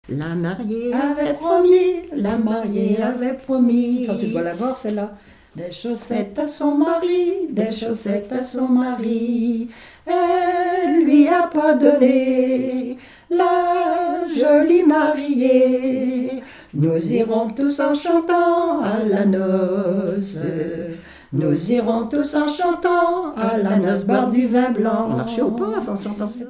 Mémoires et Patrimoines vivants - RaddO est une base de données d'archives iconographiques et sonores.
en duo avec sa sœur
Genre énumérative
Pièce musicale inédite